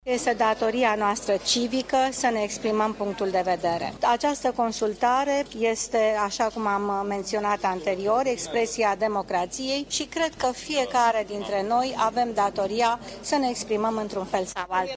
În această dimineaţă a votat şi premierul Viorica Dăncilă, în secţia deschisă la liceul „Jean Monnet” din Capitală. Ea a declarat că tema familiei este importantă pentru noi toţi şi a spus că a votat pentru valorile în care crede: